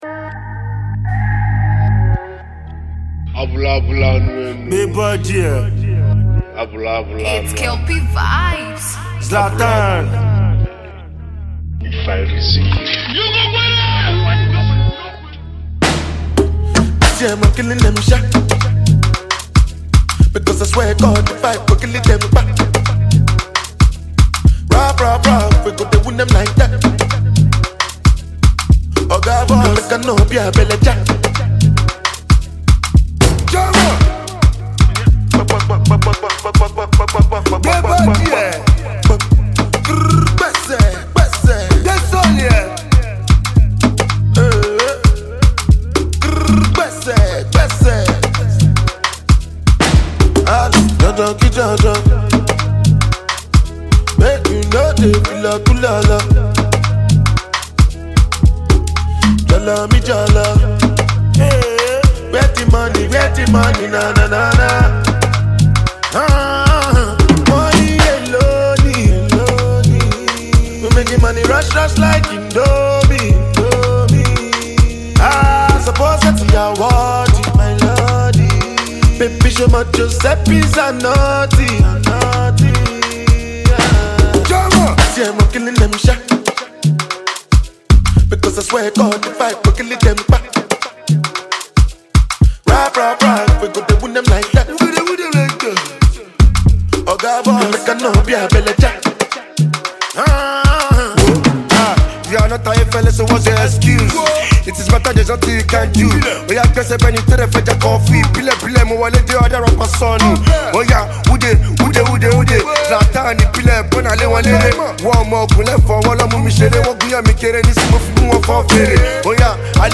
eccentric jam